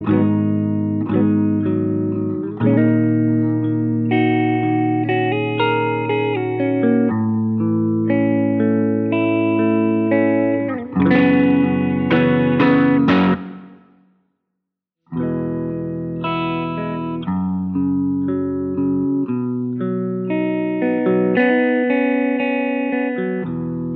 Acoustic Pop (2010s) 4:15 Buy £1.50